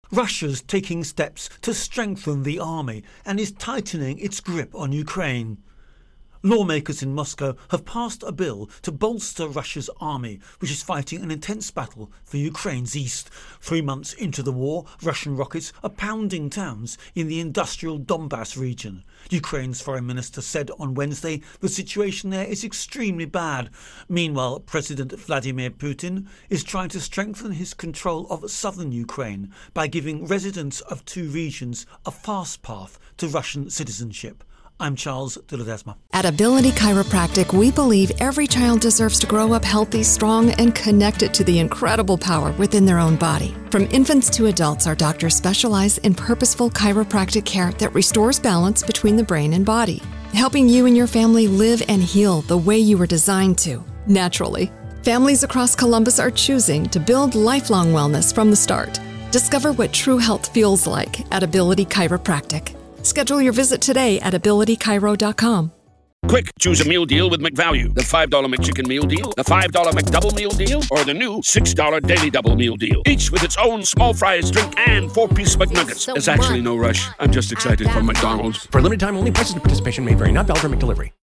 Russia Ukraine War Developments Intro and Voicer